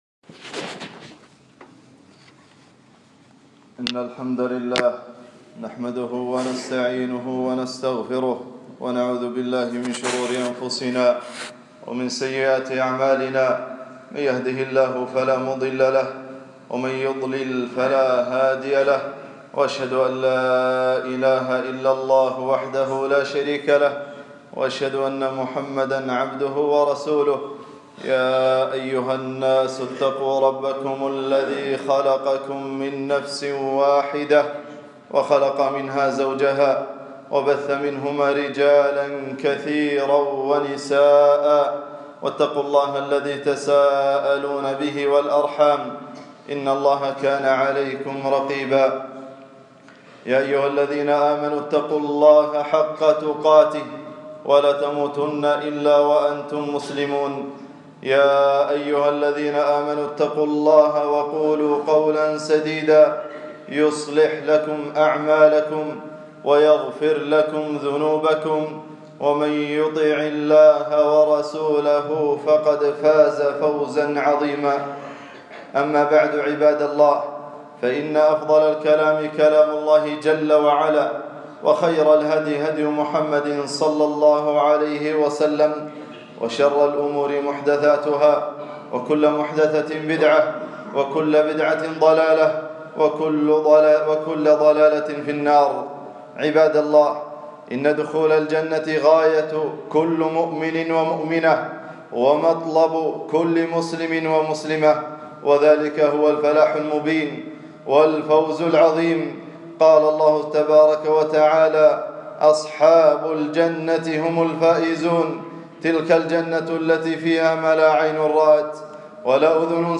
خطبة - سبل مرافقة النبي ﷺ في الجنة 1440